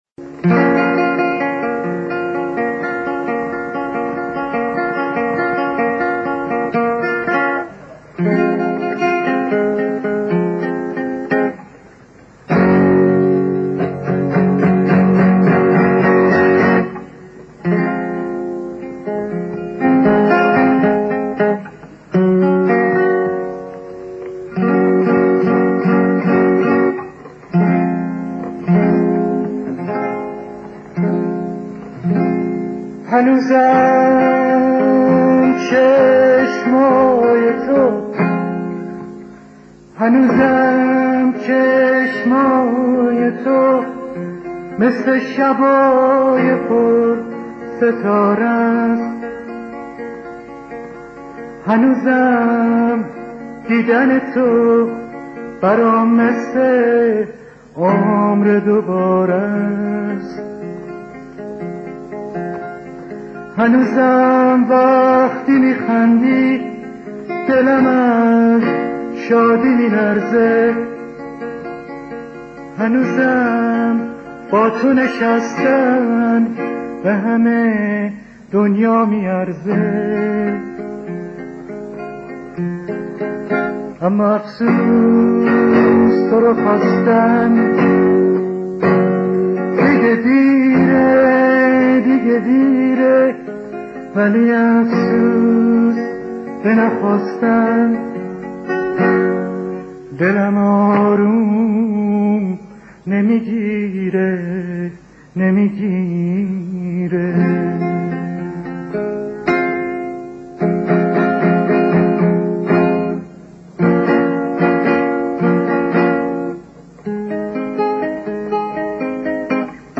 آخ چقد دلم میلرزه..این آهنگ خیلی دلگیره..